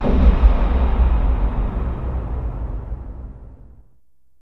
Space Impacts | Sneak On The Lot